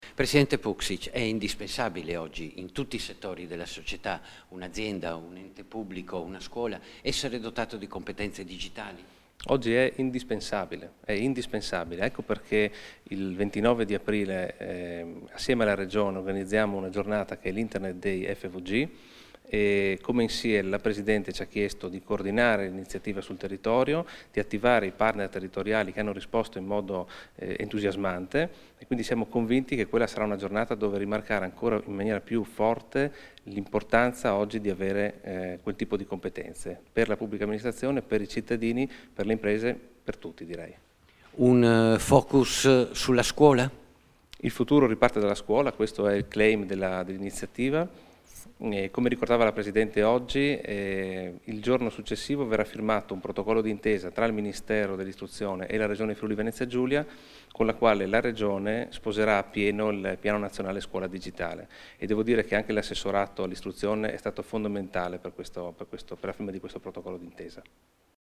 Dichiarazioni
a margine della presentazione dell'evento "internet day fvg / id fvg", rilasciate a Trieste il 12 aprile 2016